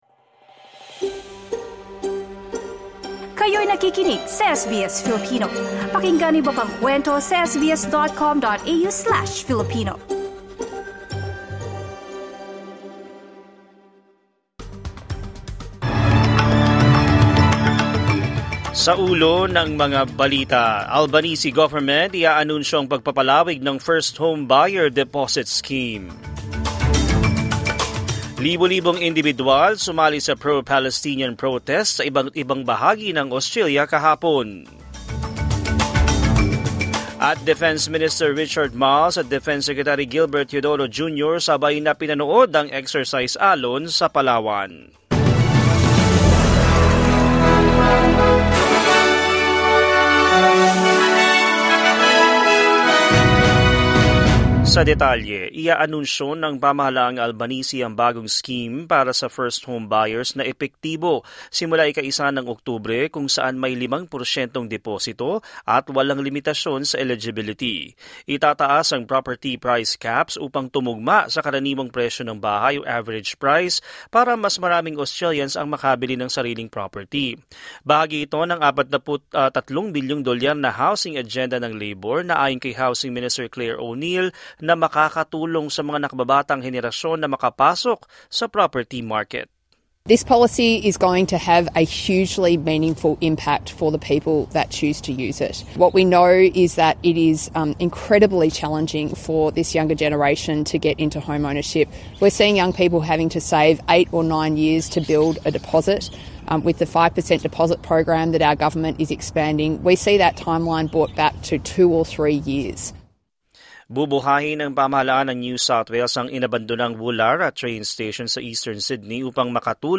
SBS News in Filipino, Monday 25 August 2025